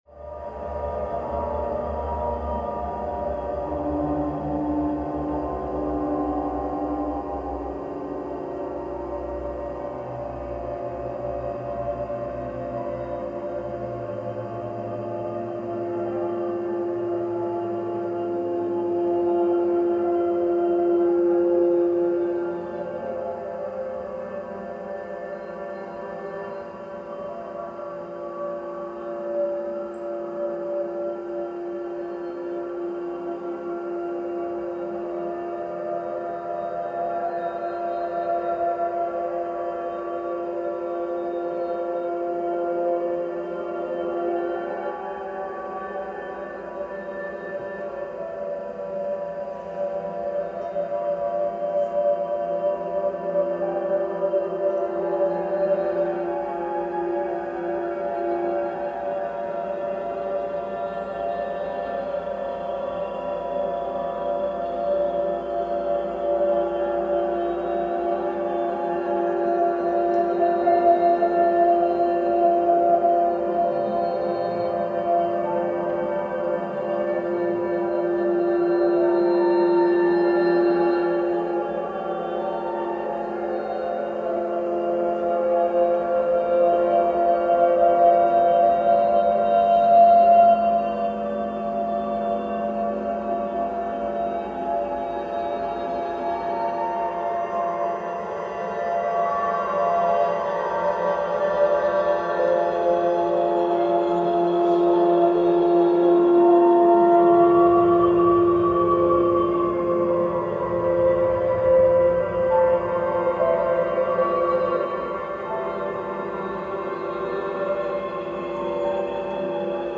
Recorded at the Loft, Cologne